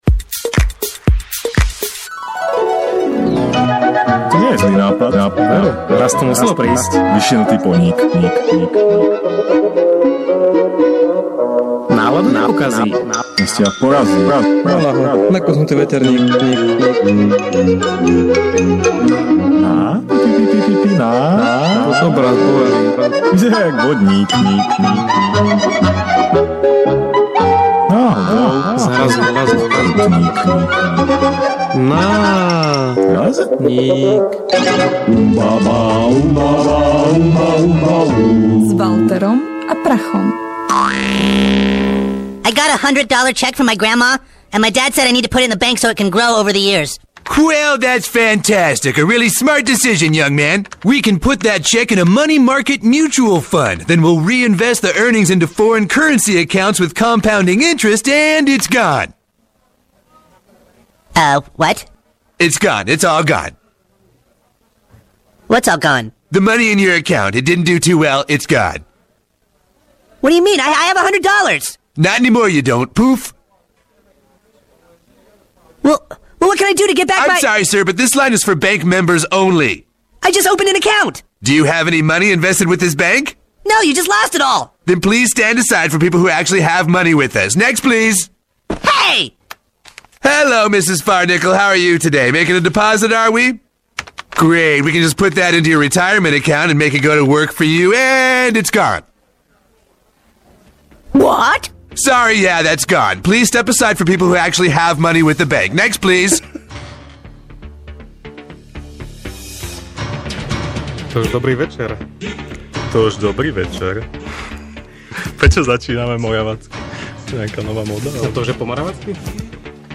Podcasty NÁRAZNÍK / Ukecaná štvrtková relácia rádia TLIS NÁRAZNÍK #35 / Von oknom 23. februára 2014 Za aké všetky nezmysly vyhadzujeme peniaze a tvárime sa, že generujeme činnosť?